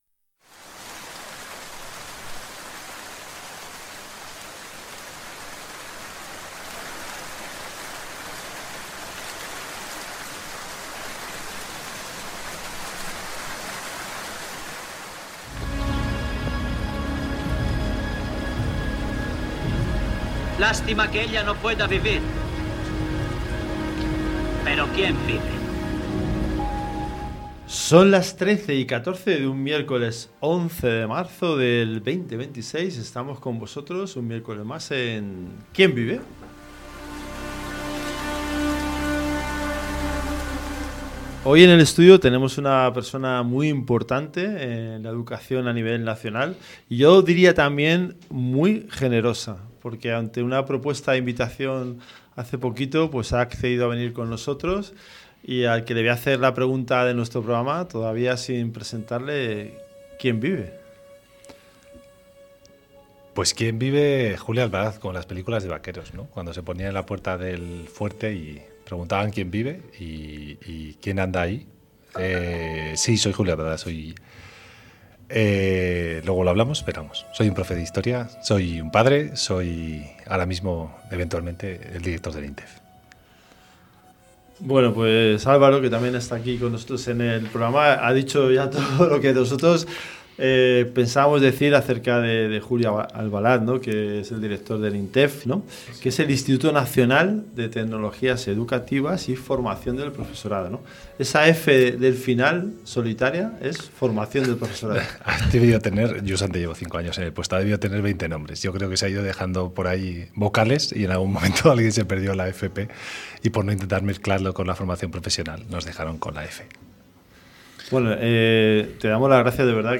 En el episodio de ¿Quién vive? de hoy, tenemos el placer de charlar con Julio Albalad Gimeno, Director del INTEF del Ministerio de Educación, Formación Profesional y Deportes.